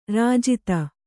♪ rājita